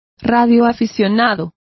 Complete with pronunciation of the translation of hams.